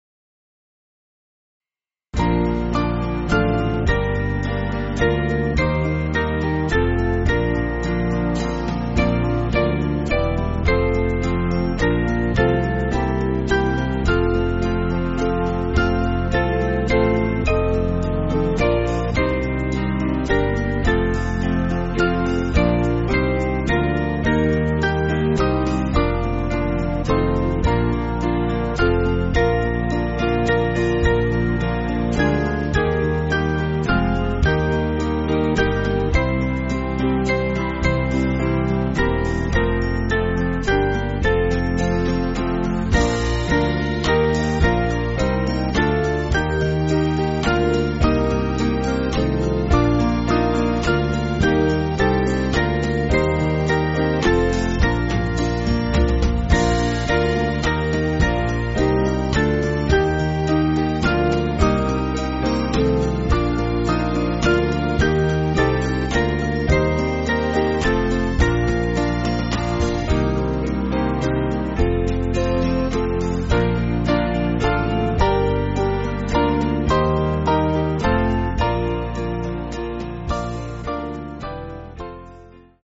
Small Band
(CM)   3/Bb